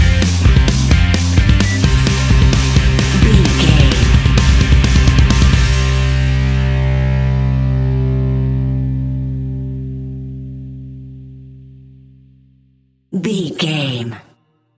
Fast Punk Music Stinger.
Epic / Action
Ionian/Major
hard rock
distortion
punk metal
rock instrumentals
Rock Bass
heavy drums
distorted guitars
hammond organ